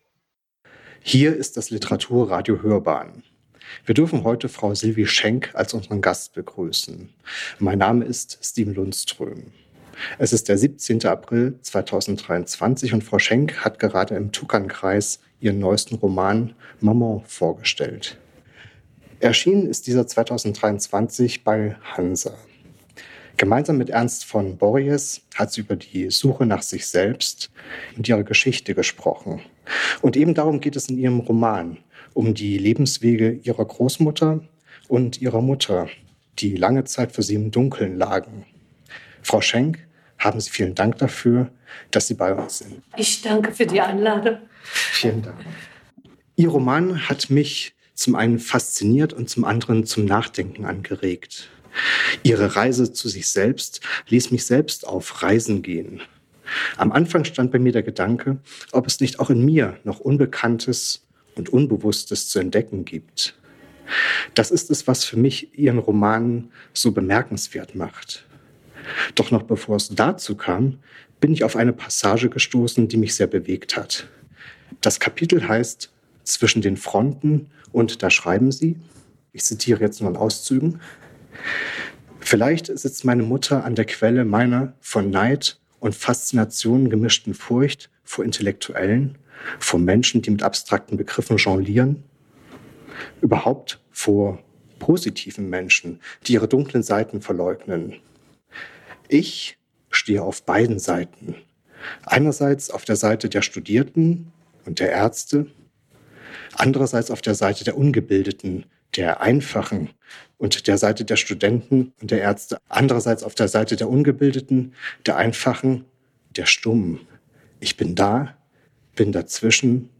Tukan-Sylvie-Schenk-Interview-upload.mp3